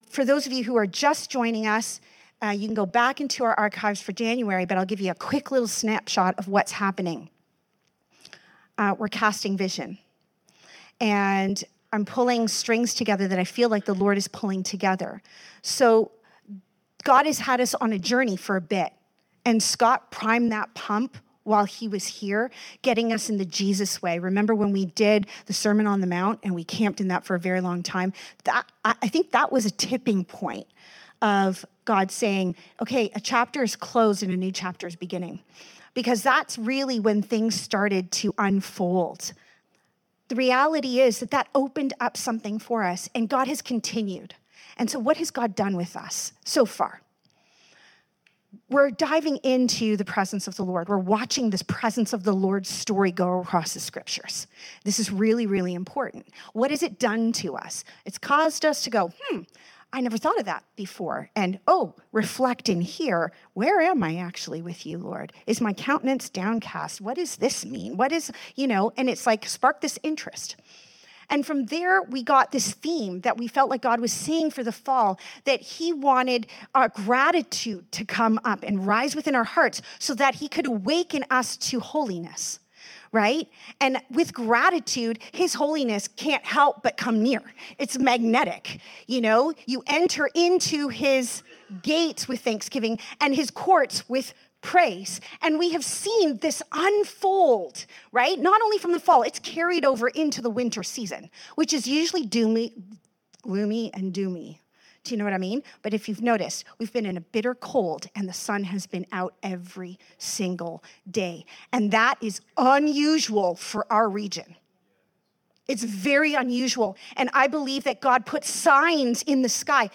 Individual Teachings Service Type: Sunday Morning As we step into 2025